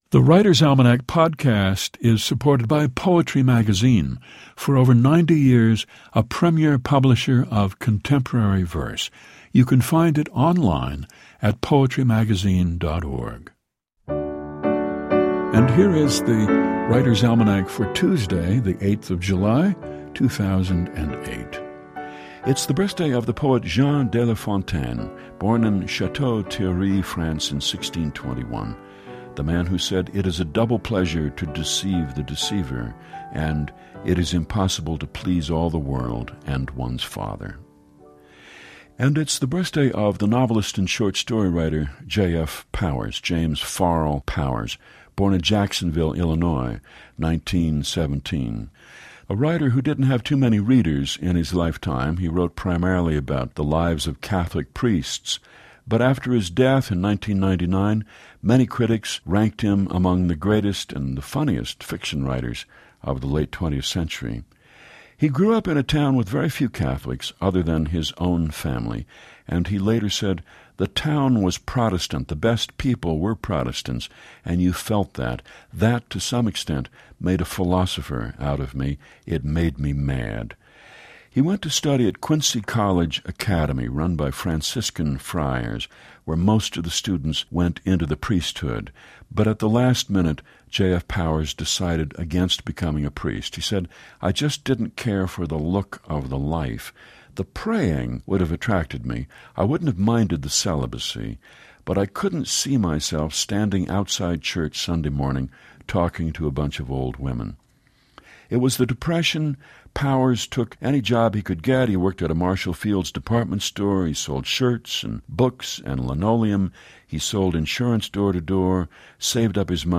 Each day, The Writer's Almanac features Garrison Keillor recounting the highlights of this day in history and reads a short poem or two.